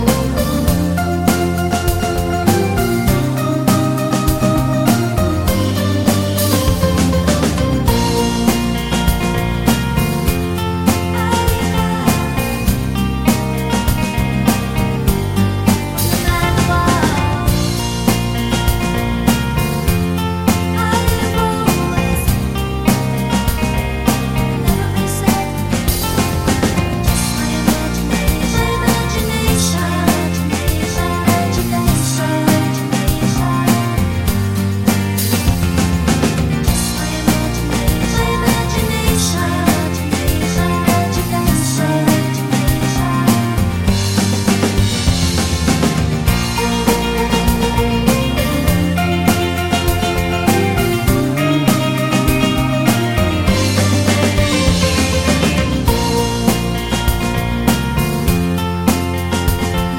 no Backing Vocals Irish 3:17 Buy £1.50